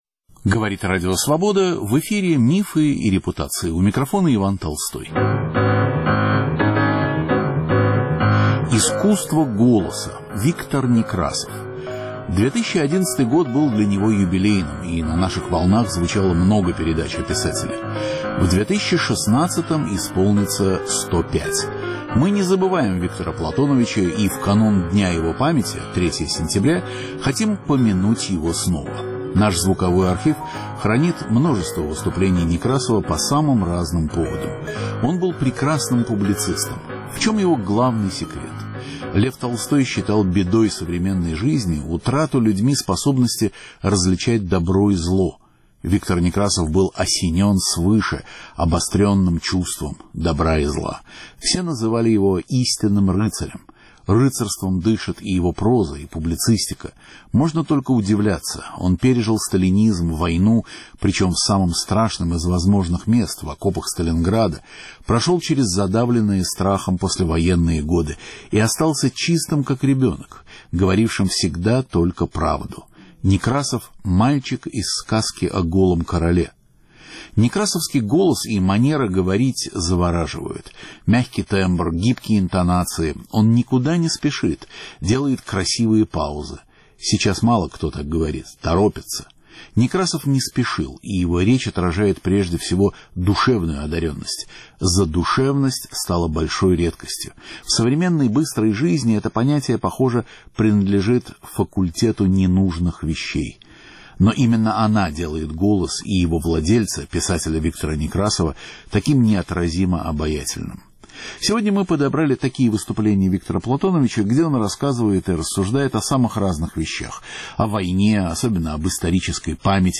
Искренний и обаятельный голос подобен музыке. Таким голосом обладал писатель Виктор Некрасов.